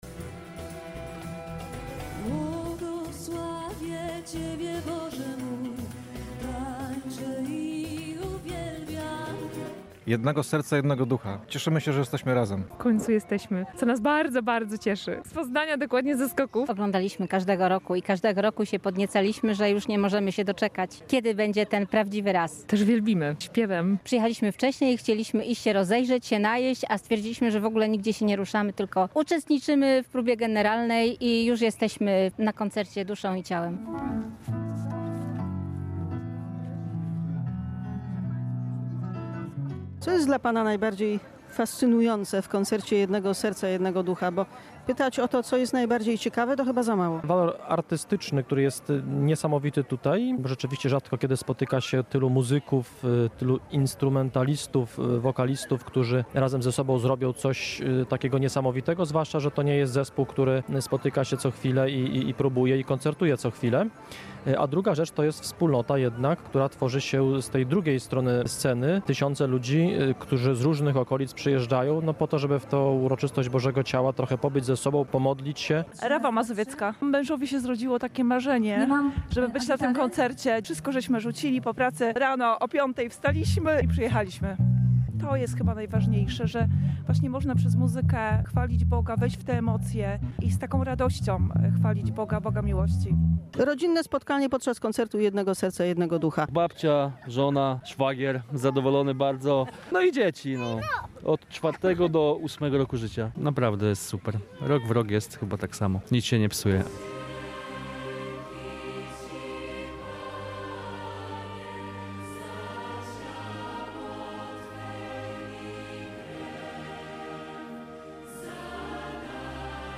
Przyjechali z Poznania, Krakowa, Sanoka, a nawet z Los Angeles. W uroczystość Bożego Ciała wszystkie drogi prowadziły do rzeszowskiego Parku Sybiraków: